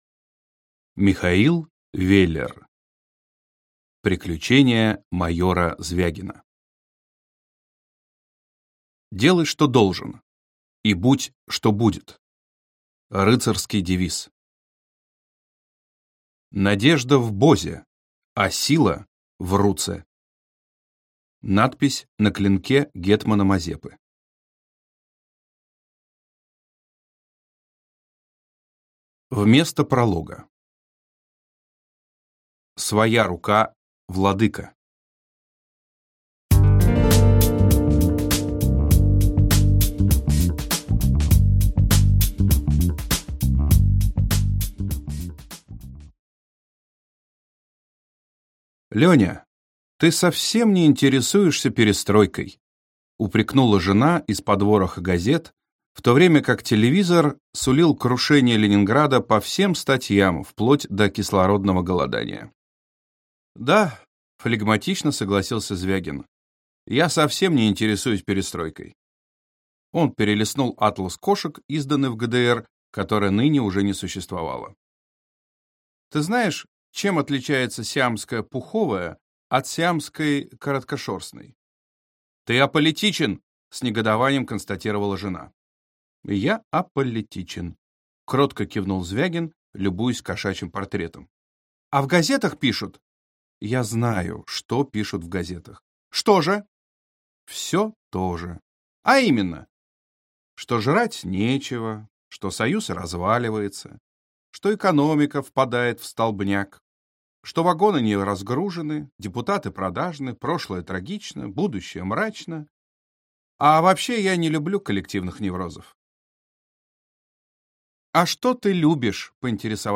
Аудиокнига Приключения майора Звягина | Библиотека аудиокниг